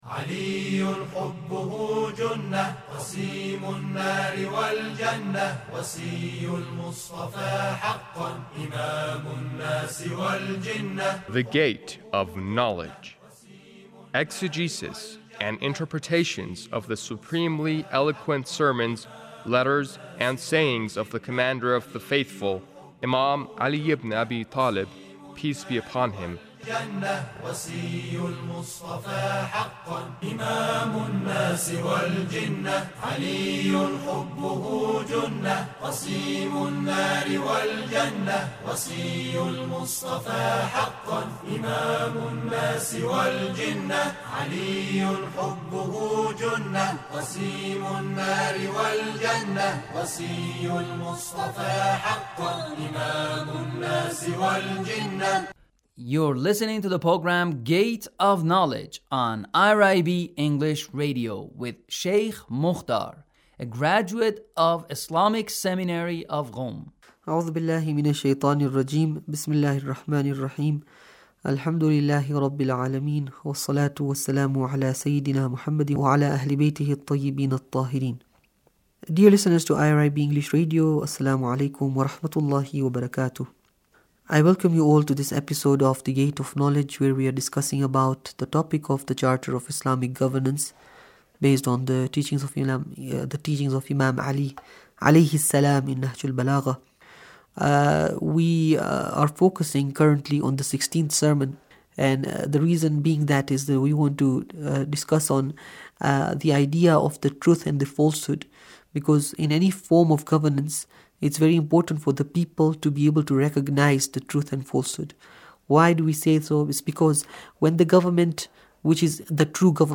Sermon 1 -